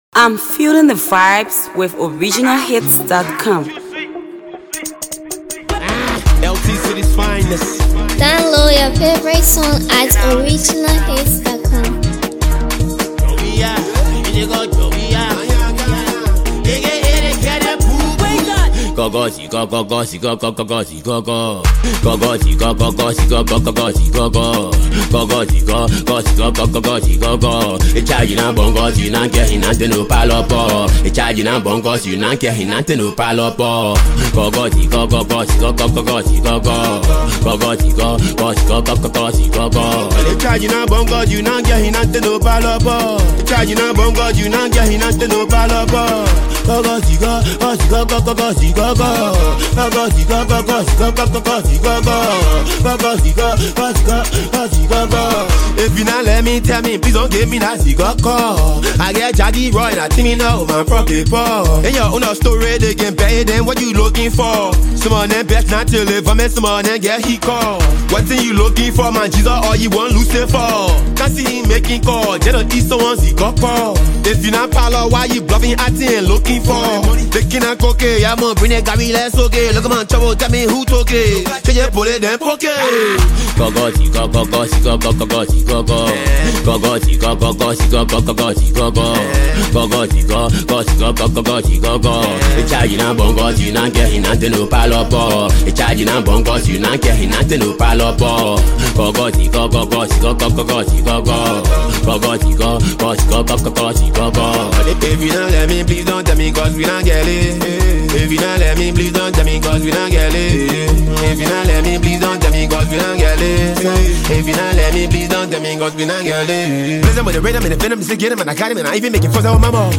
Liberian Hipco star
is a vibrant, danceable banger